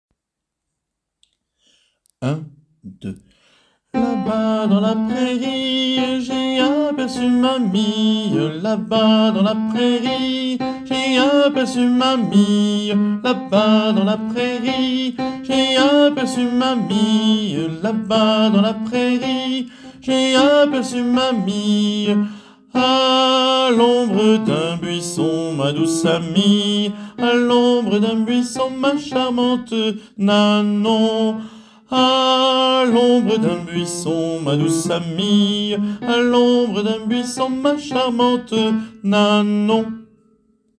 Téléchargez la voix des altos